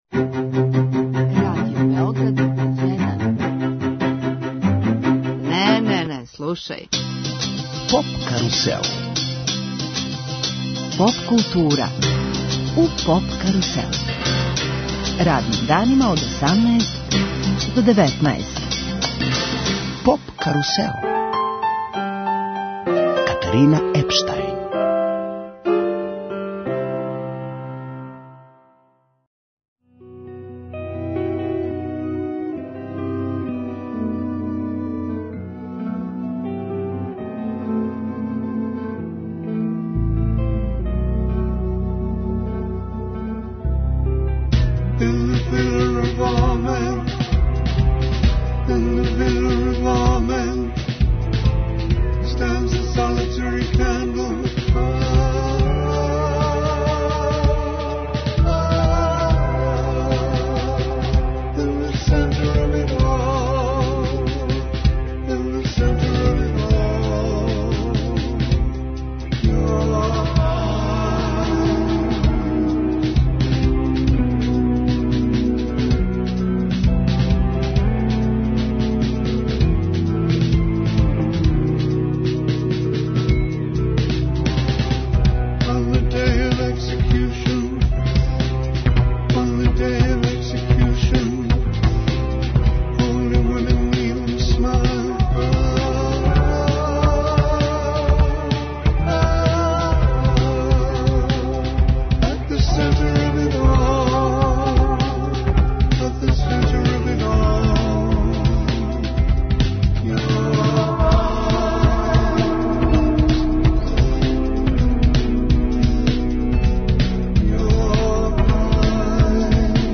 У нашем студију ексклузивно чланови једног од најпопуларнијих музичких састава у региону, Хладно пиво.